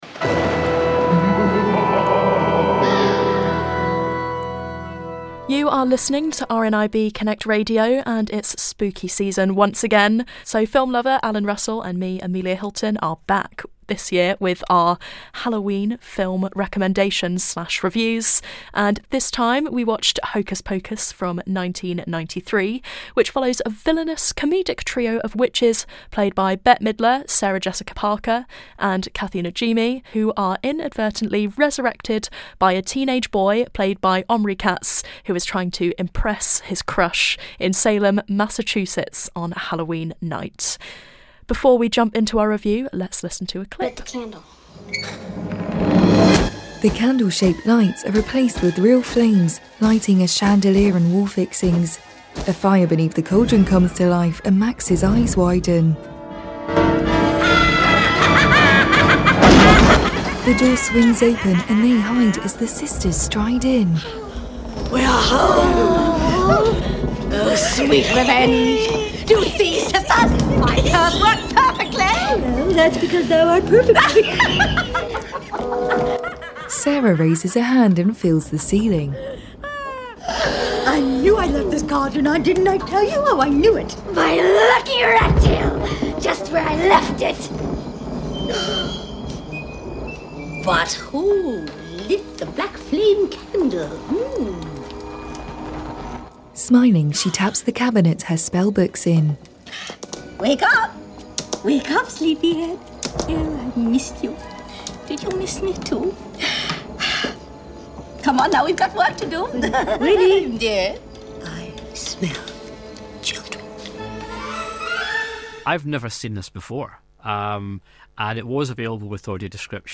AD Movie Review - Hocus Pocus